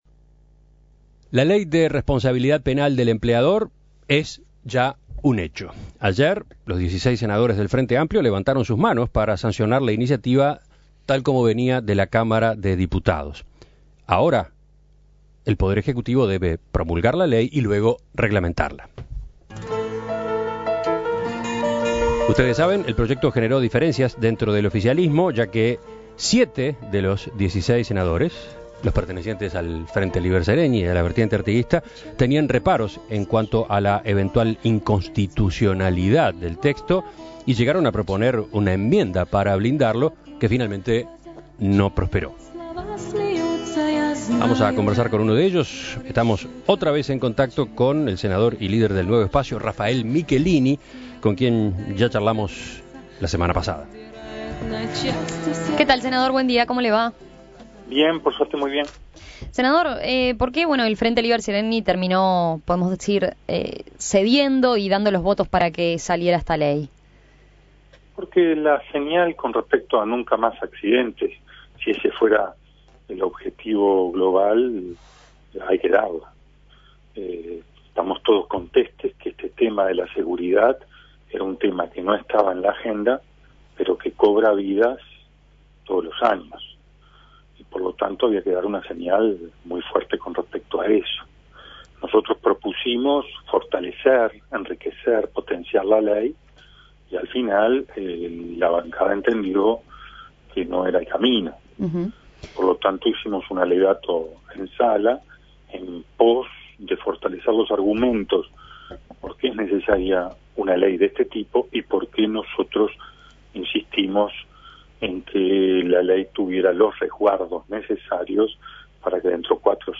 Luego de la polémica en torno a la Ley de Responsabilidad Penal del Empleador, el Frente Amplio (FA) hizo uso de su mayoría parlamentaria para aprobarla, a pesar de los diferendos internos. En el día después de la larga jornada parlamentaria, En Perspectiva dialogó con el senador del Nuevo Espacio Rafael Michelini, quien sostuvo que a pesar de las diferencias, el objetivo principal era "dar la señal" de "nunca más accidentes" en materia laboral.